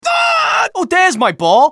scout_paincrticialdeath03.mp3